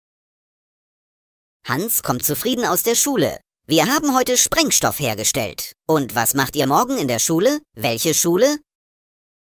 Die Witzschmiede bringt Dir jeden Tag einen frischen Witz als Audio-Podcast. Vorgetragen von unseren attraktiven SchauspielerInnen.
Comedy , Unterhaltung , Kunst & Unterhaltung